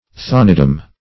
Search Result for " thanedom" : The Collaborative International Dictionary of English v.0.48: thanedom \thane"dom\ (th[=a]n"d[u^]m), n. The property or jurisdiction of a thane; thanage.
thanedom.mp3